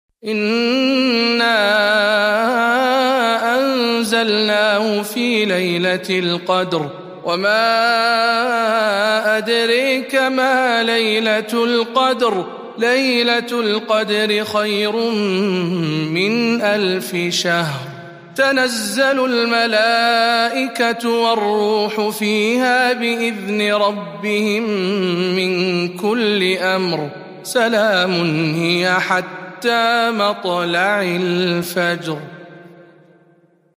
سورة القدر برواية شعبة عن عاصم